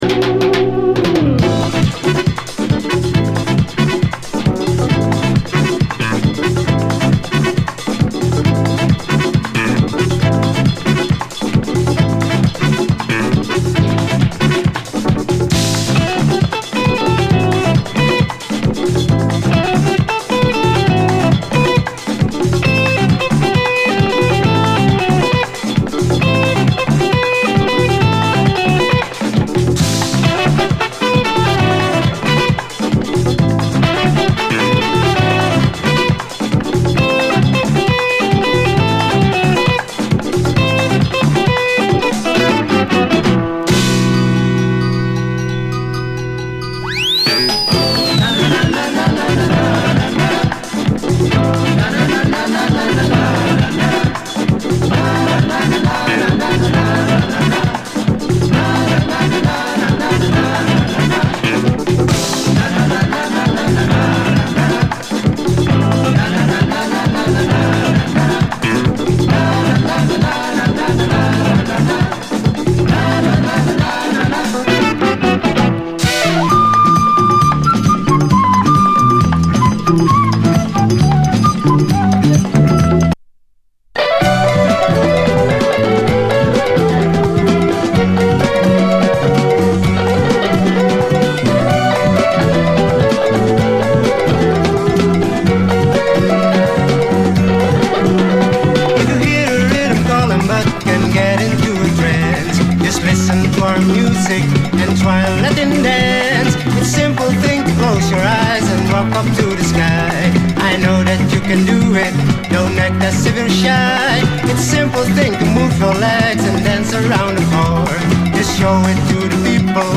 SOUL, 70's ROCK, 70's～ SOUL, LATIN, ROCK
オランダ産ラテン・ロック！